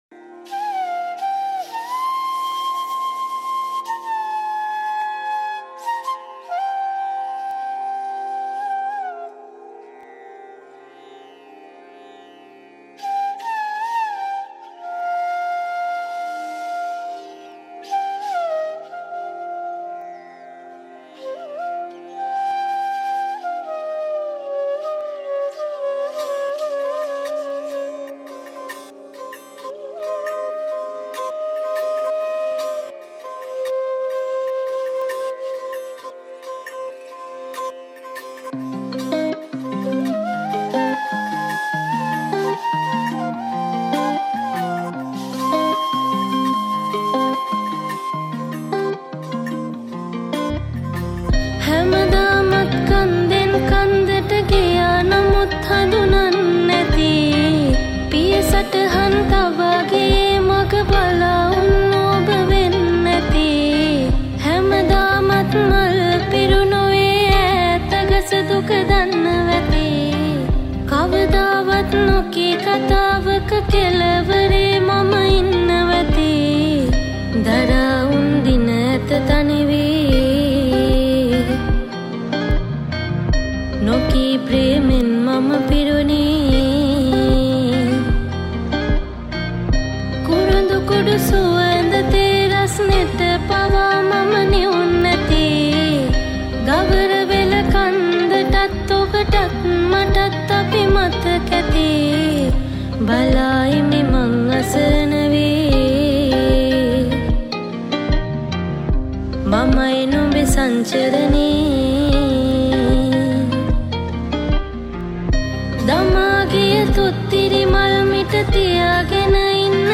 Bass guitar
Flute